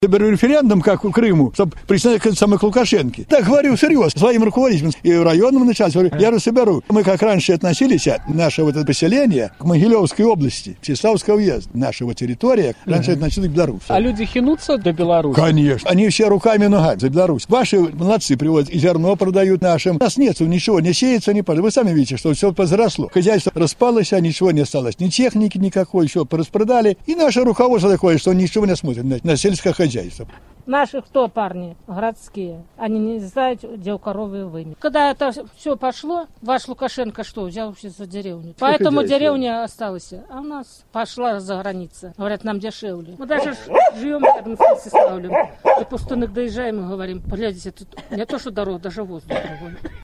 У гаворцы любавіцкіх жыхароў таксама чуваць беларушчына.
Гаворыць жыхар вёскі Любавічы